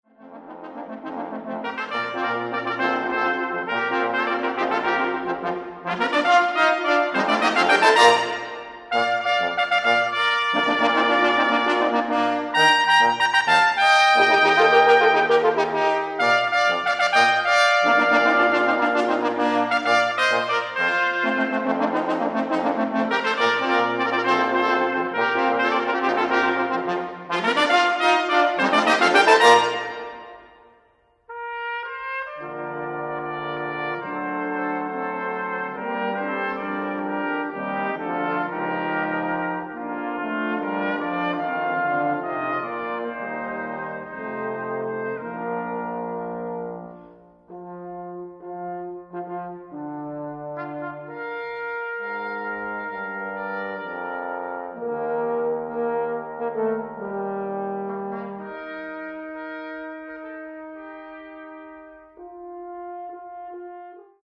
Australian, Classical